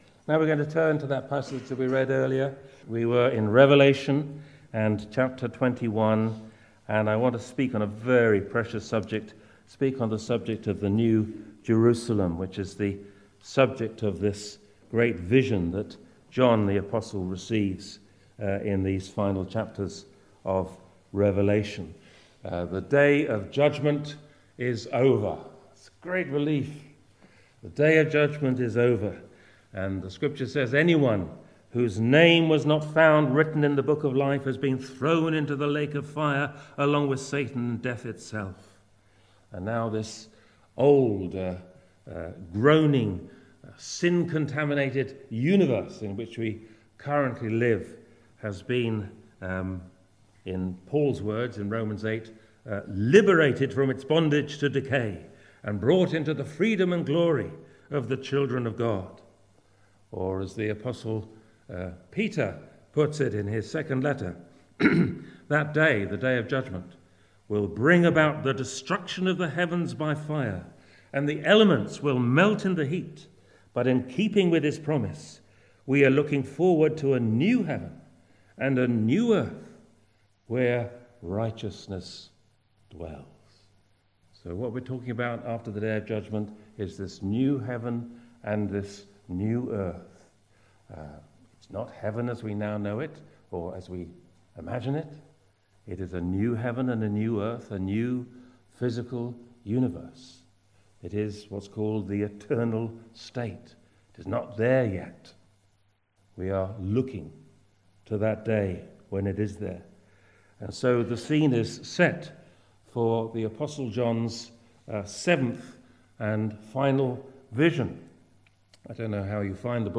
Passage: Revelation 21:1-22:5 Service Type: Sunday Morning